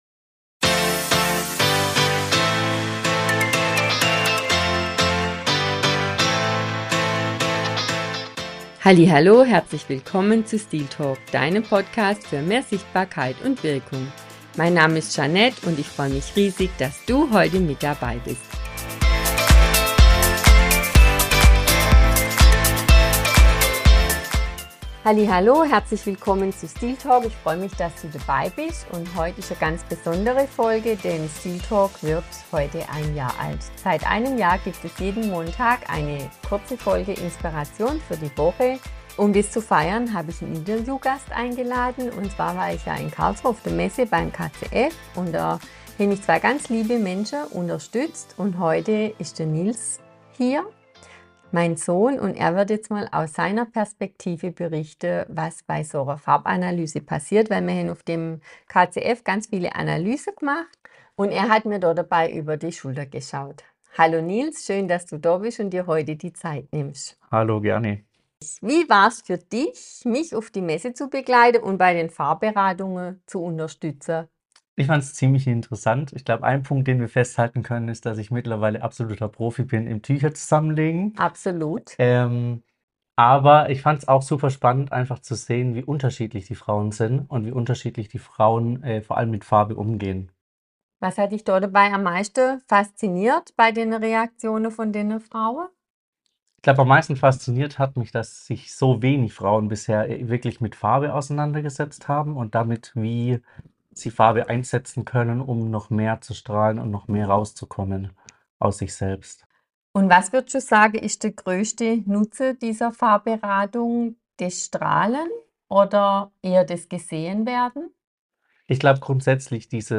055 - Farbberatung live erleben - ein Interview ~ Stiltalk Podcast